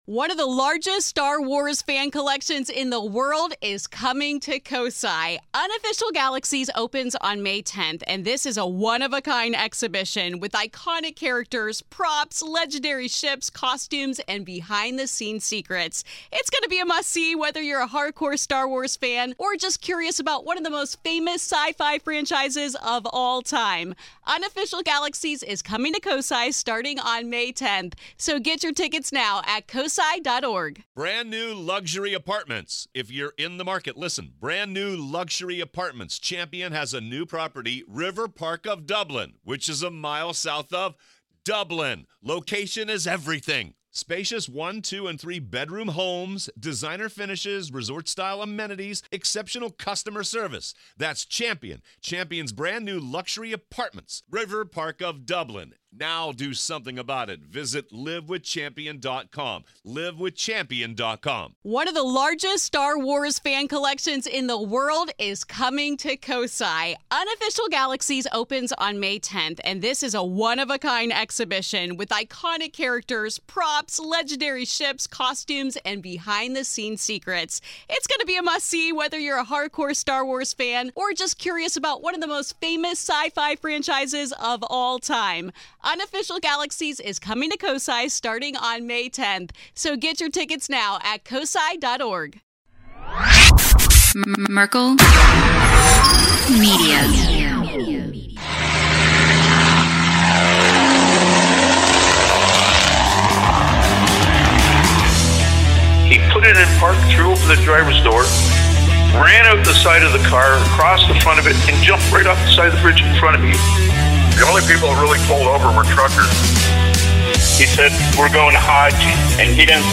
In this episode of Hammer Lane Legends, the hosts dive into a lively conversation filled with personal stories, workplace quirks, and the frustrations of life on the road.